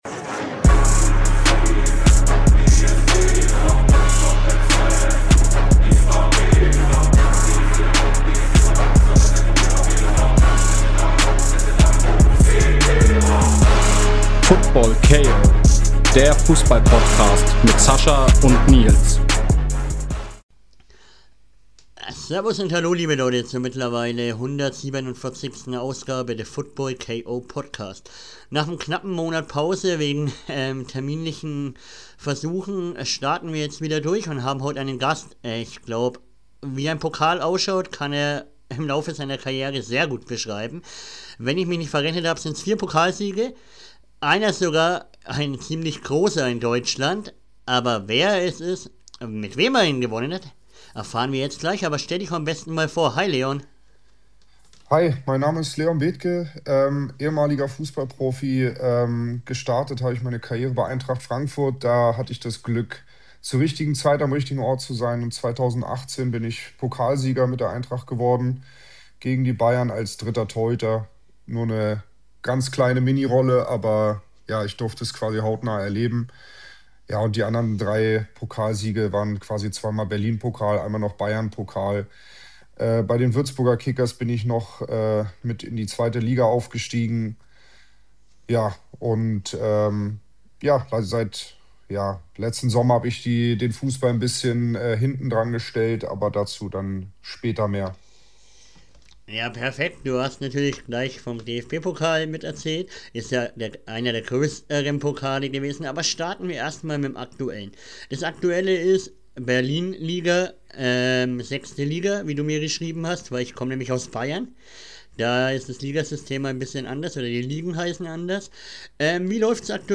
Heute haben wir wieder mal einen Torhüter am Start.